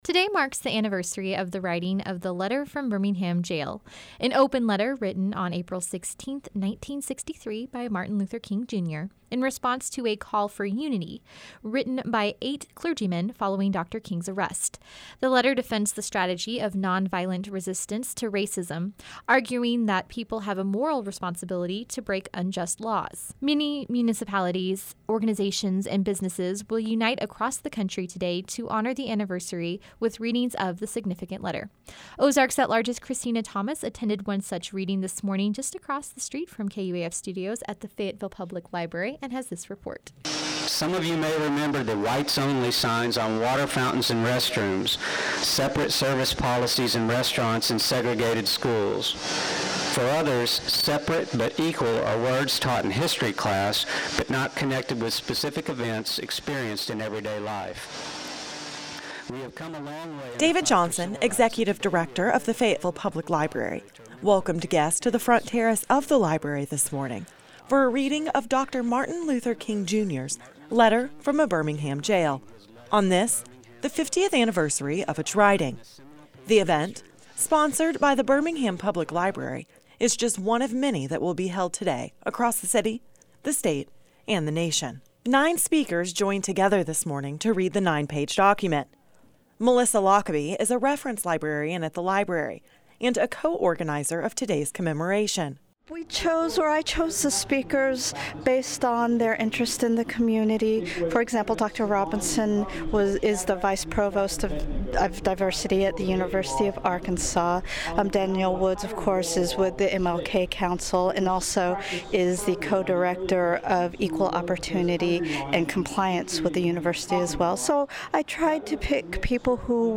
This morning, a reading of the letter was held at the Fayetteville Public Library